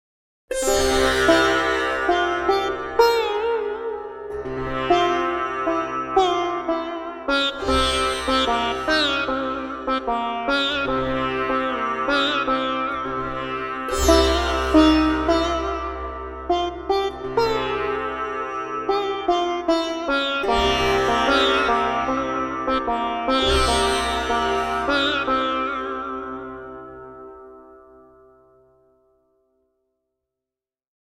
Individual drones and long full decay samples. Sampled in 24 bit.
Recorded in stereo (has individual left and right mic soundsets) and also includes a mono Soundset of the 2 mics blended.
Manytone_Sitar_Demo.mp3